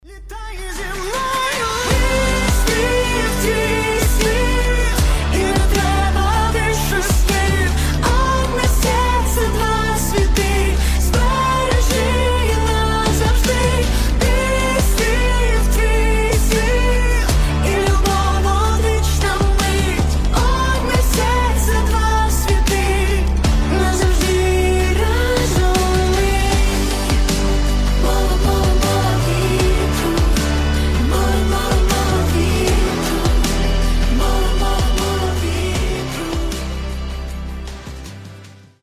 • Качество: 320, Stereo
красивые
саундтреки
дуэт